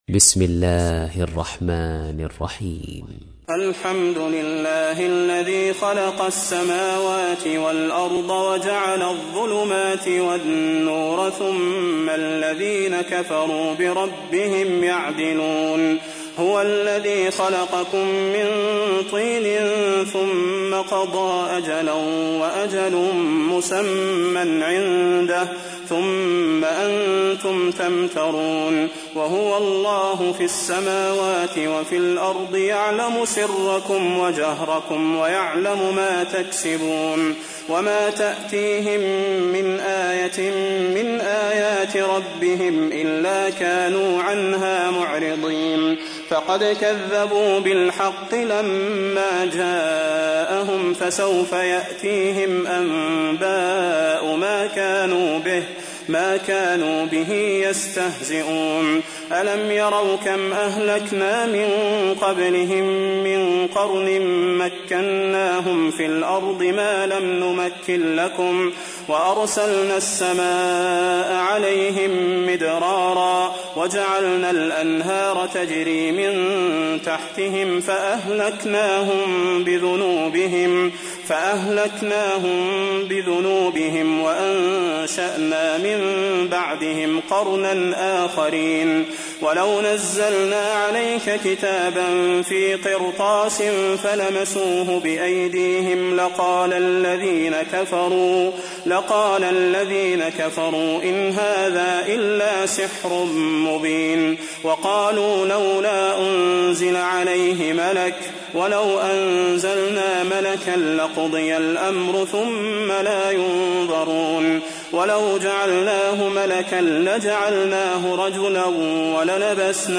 سورة الأنعام / القارئ صلاح البدير / القرآن الكريم / موقع يا حسين